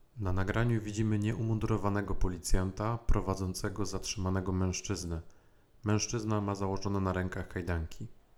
Audiodeskrypcja do filmu